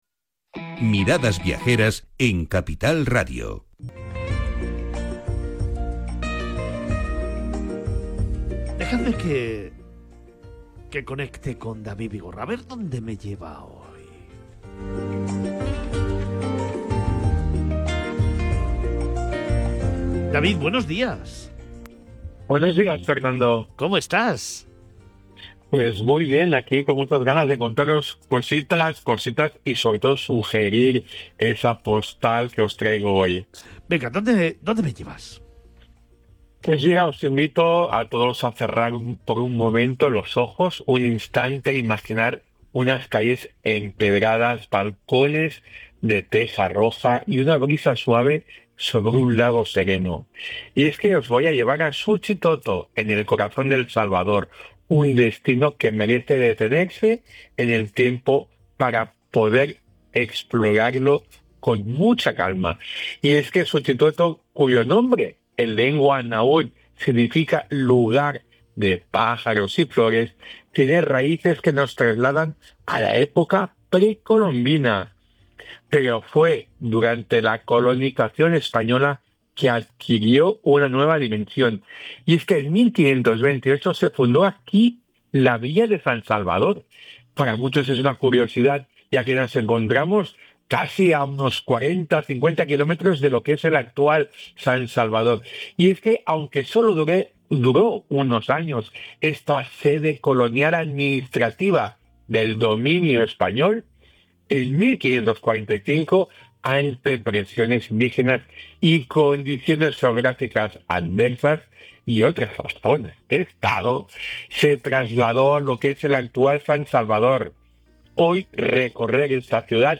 Miradas Viajeras se emite en Capital Radio, una emisora centrada en la información económica y empresarial. Dentro de su programación, el programa abre una ventana al mundo del turismo, la cultura y las grandes historias que se esconden detrás de cada lugar.